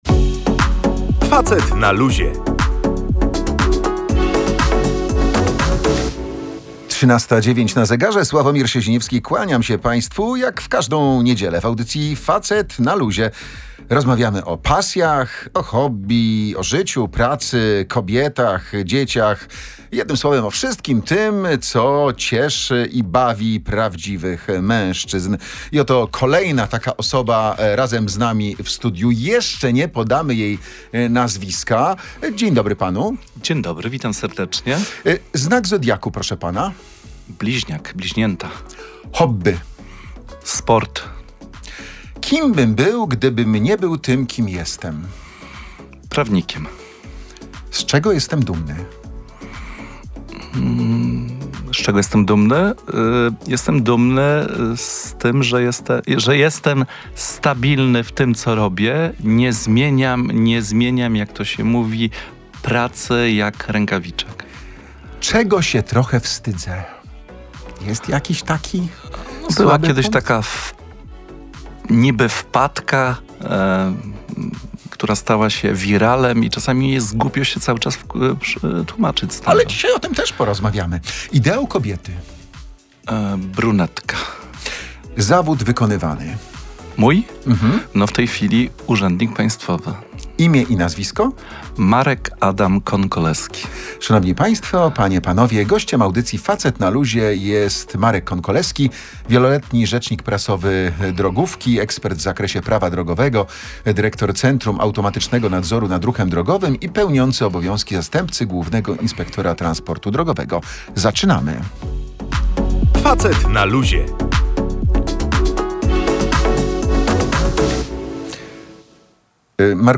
Marek Konkolewski, obecnie pełniący obowiązki zastępcy Głównego Inspektora Transportu Drogowego, był gościem audycji „Facet na Luzie”.